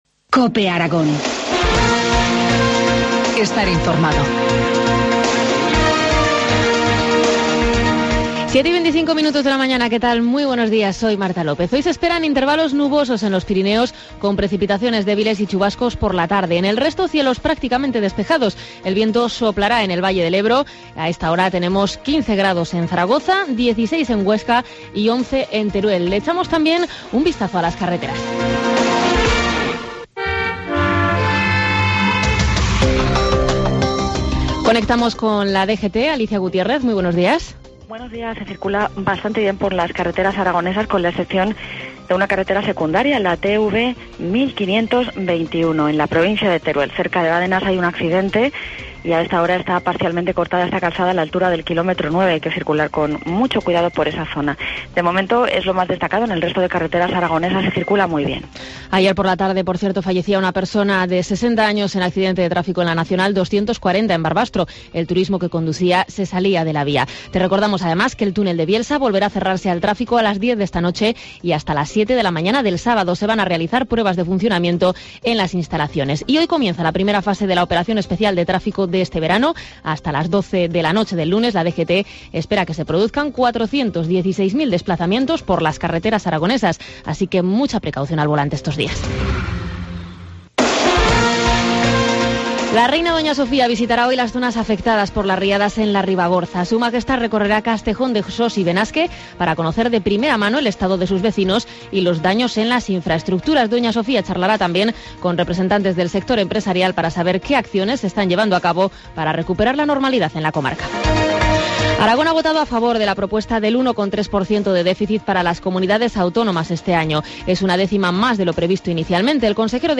Informativo matinal, viernes 28 de junio, 7.25 horas